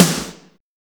SHOCK SNR.wav